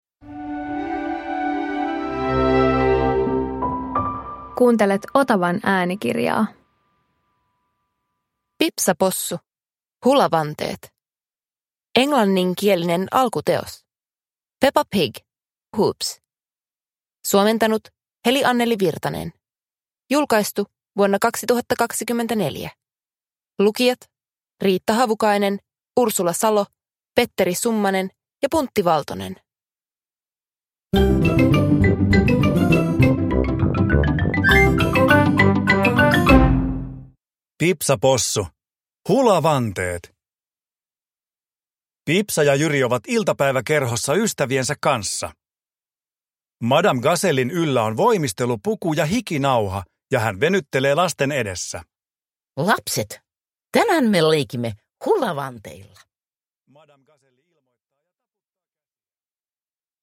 Pipsa Possu - Hulavanteet – Ljudbok